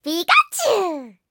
Cri de Pikachu dans Pokémon HOME.